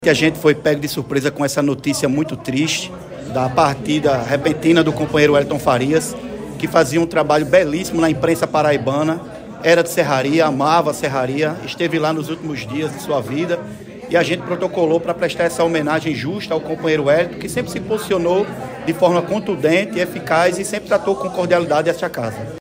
O vereador Junio Leandro, autor do ‘Requerimento de Voto de Pesar‘, no documento se referiu ao jornalista como “um companheiro atuante na luta por uma sociedade mais justa e igualitária e um ser humano exemplar” e, ao falar com a reportagem do programa Correio Debate, da 98 FM, de João Pessoa, se disse muito triste com a notícia.